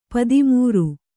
♪ padi mūru